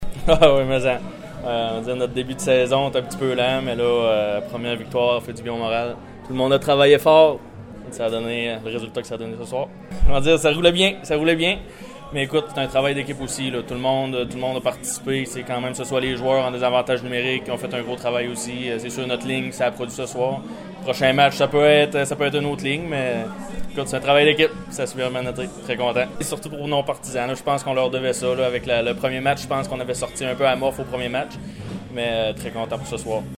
Ses commentaires d’après-match :